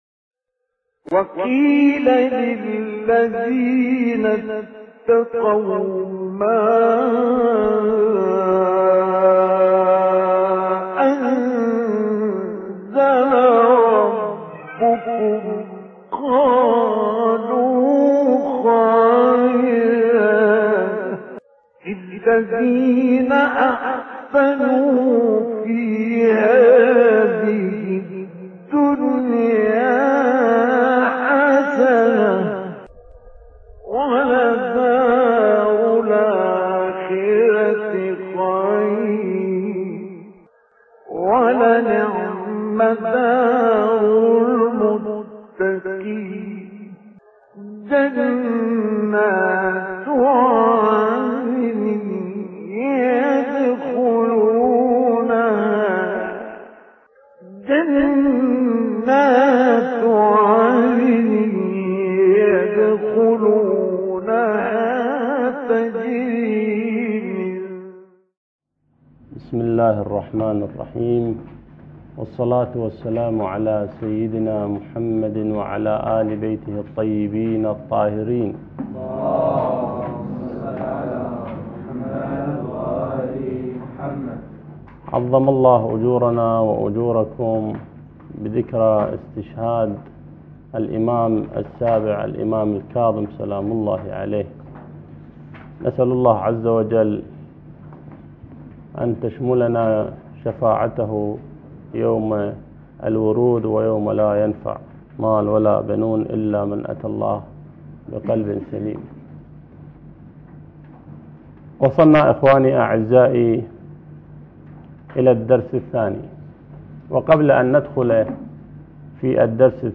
الدرس الثاني تدوين القرآن - لحفظ الملف في مجلد خاص اضغط بالزر الأيمن هنا ثم اختر (حفظ الهدف باسم - Save Target As) واختر المكان المناسب